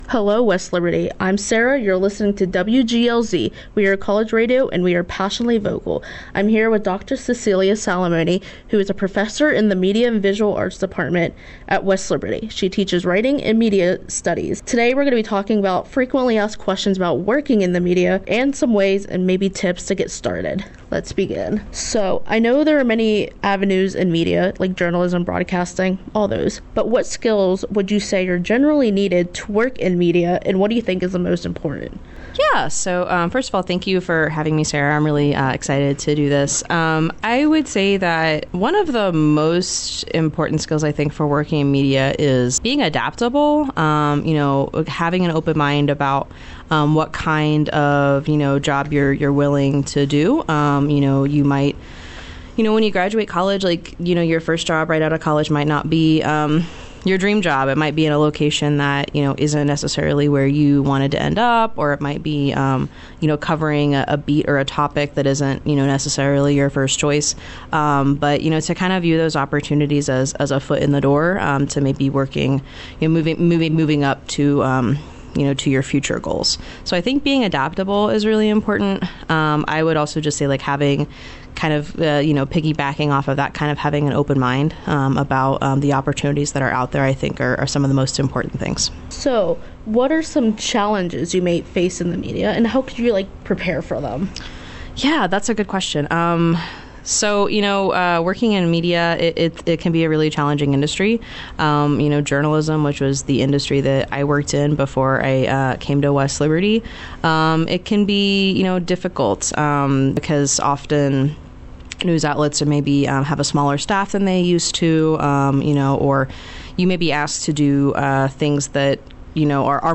Academic Affairs interview